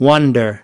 12. wonder (n) /ˈwʌndər/ kì quan